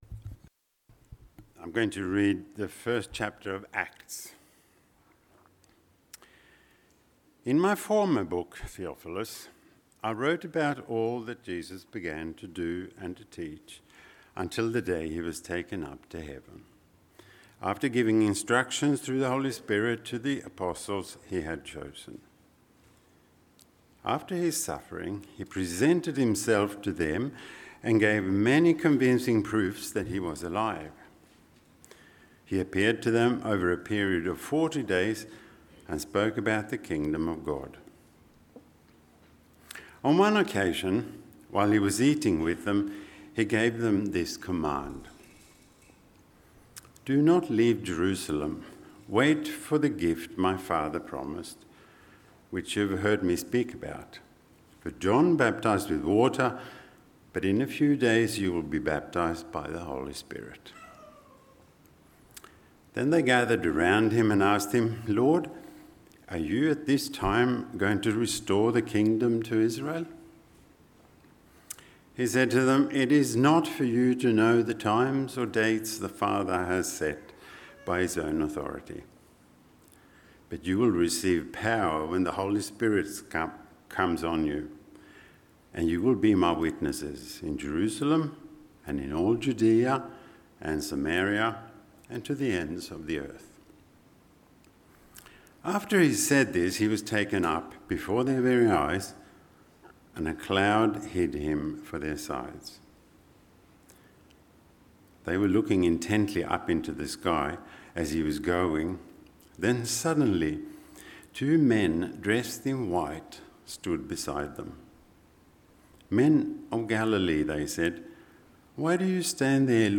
Sermons – Cairns Presbyterian Church